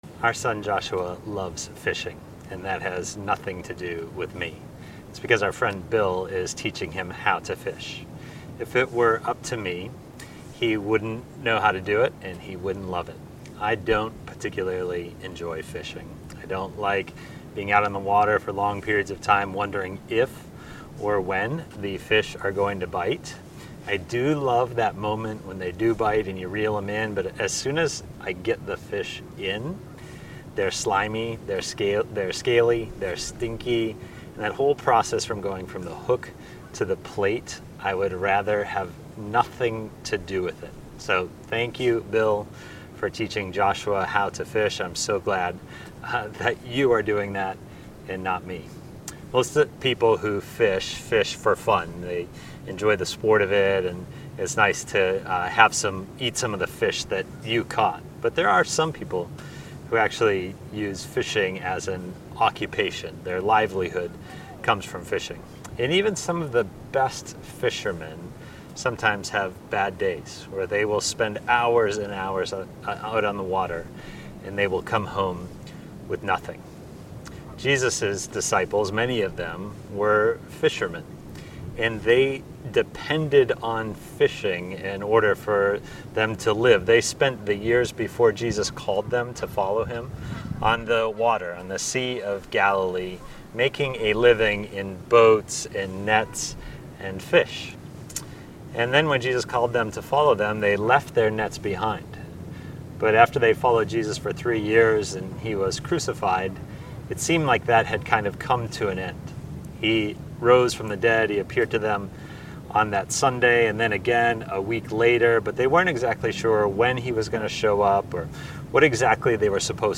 We Have Seen His Glory Passage: John 21:1-25 John 21. Sermon